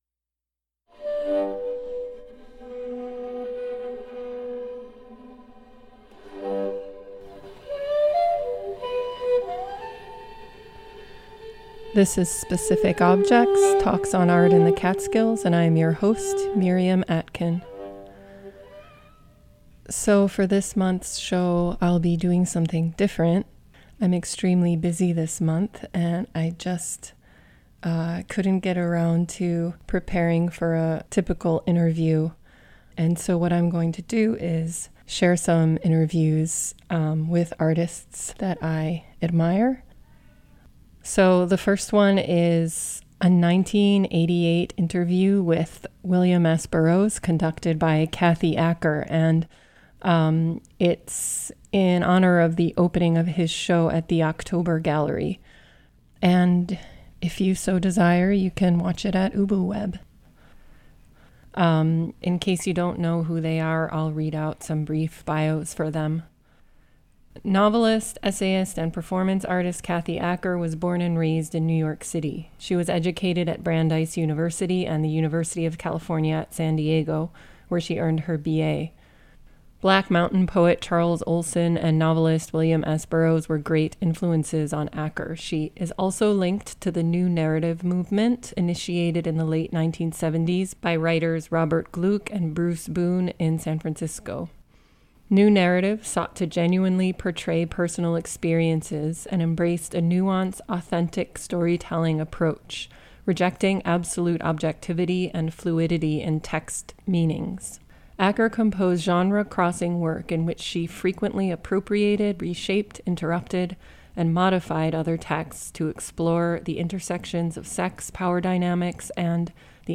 The first half of the broadcast is an interview with WIlliam S. Burroughs by Kathy Acker. The second half is an interview